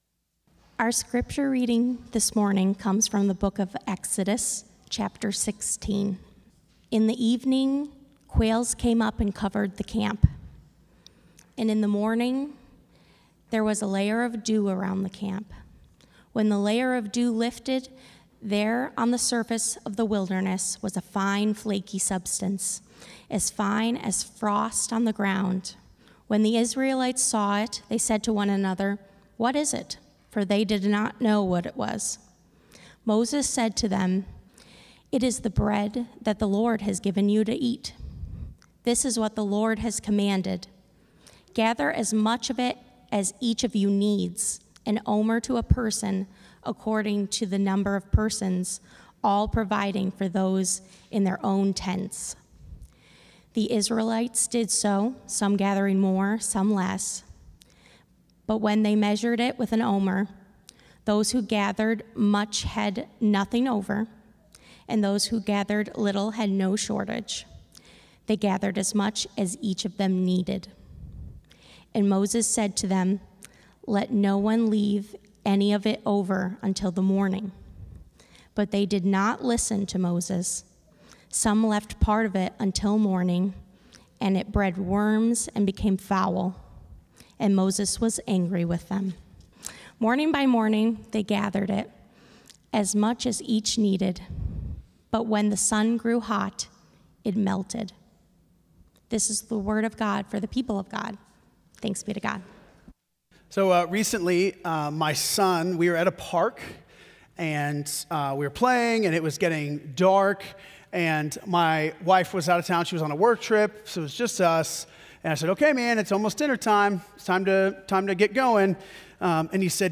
“Unhurried Advent” Sermon Series, Week 3